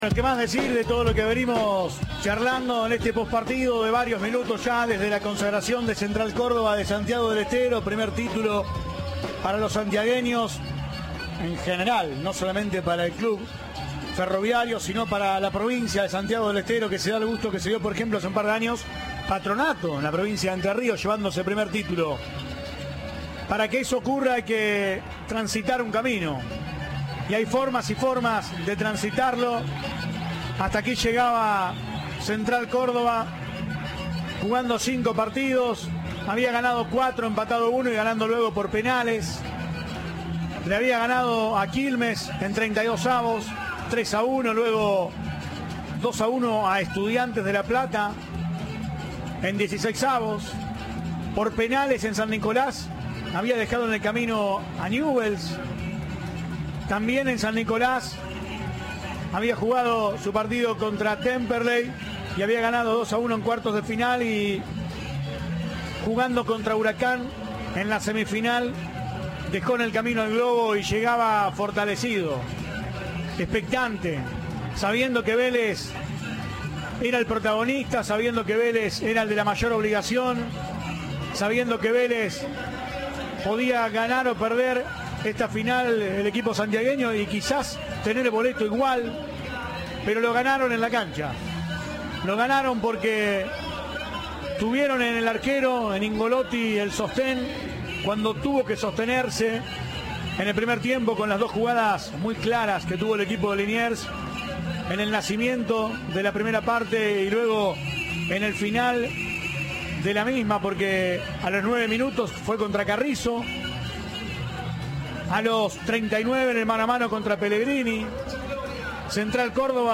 Comentario del partido